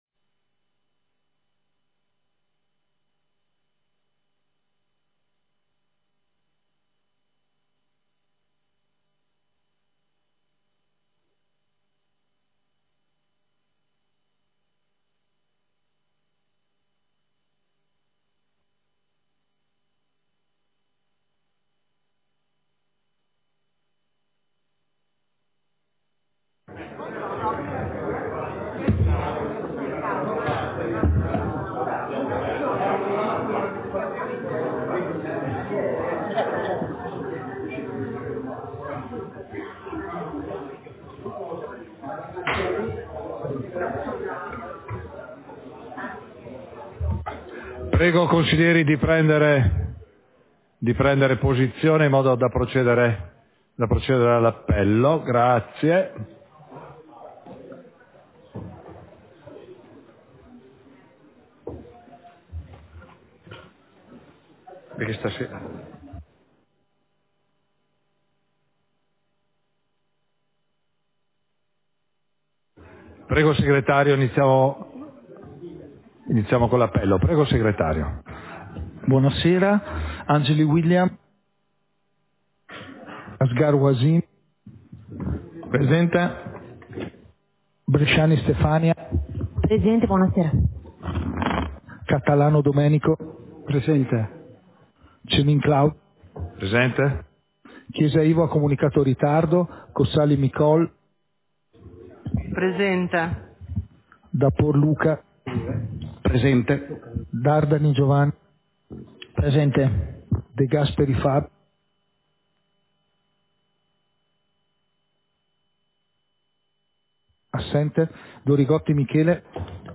Seduta del consiglio comunale - 10 dicembre 2024